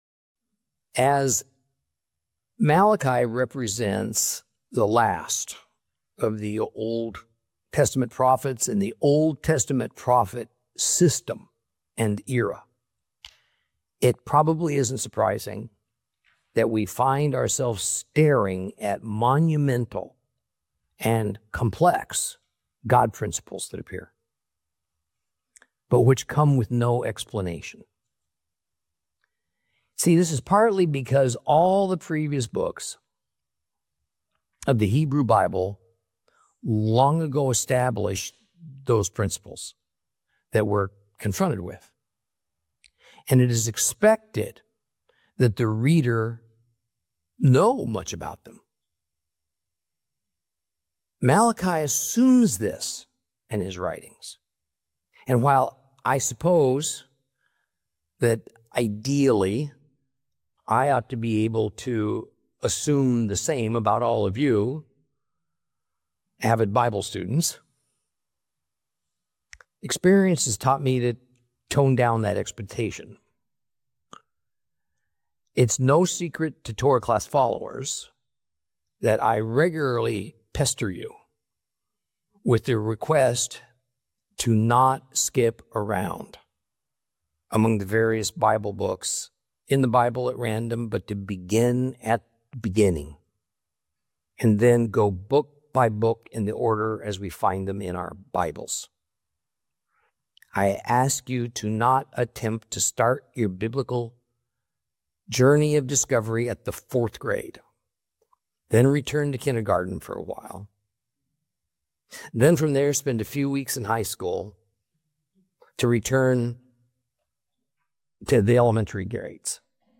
Teaching from the book of Malachi, Lesson 3 Chapter 1 continued.